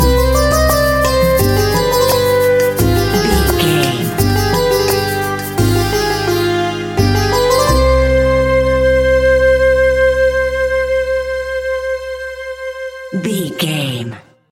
Aeolian/Minor
ethnic
World Music
percussion